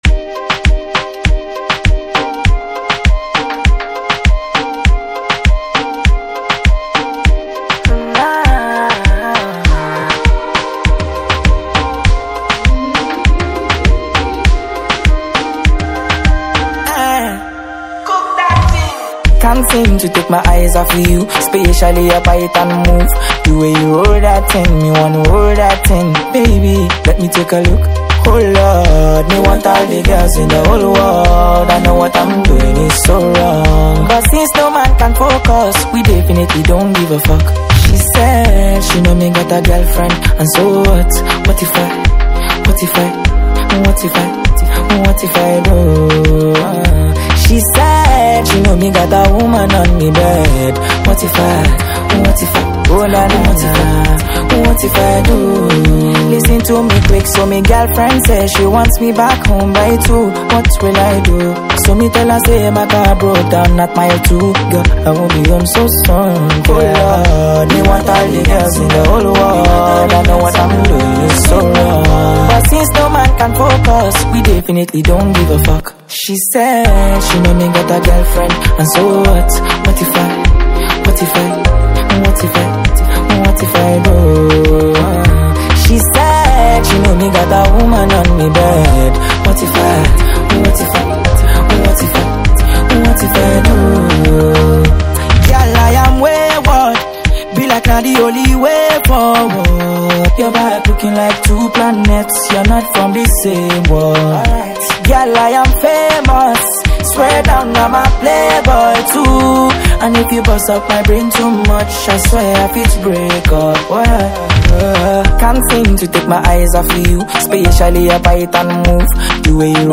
a Nigerian Afrobeat singer-songwriter